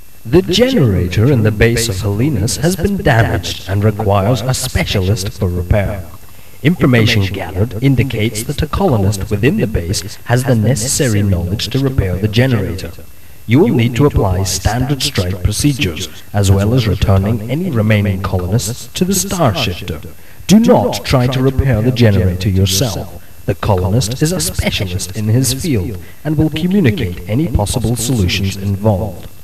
Amiga 8-bit Sampled Voice
1 channel